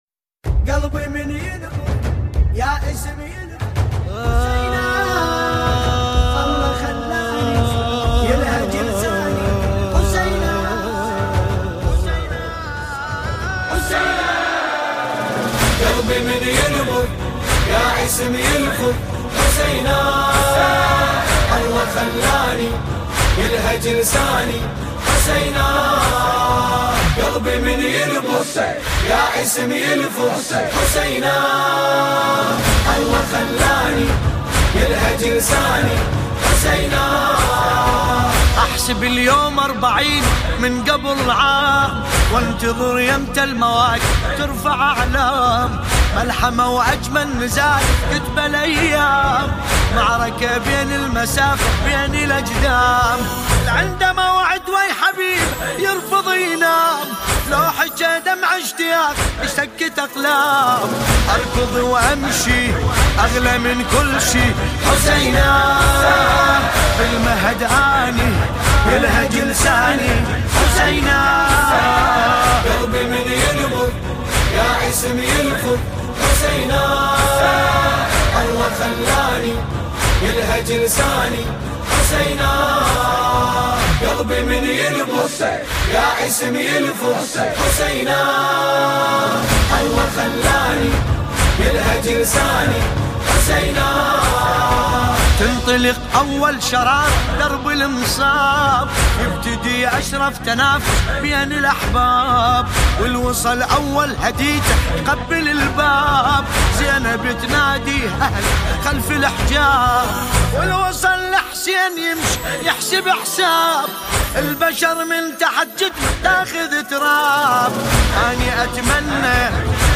سینه زنی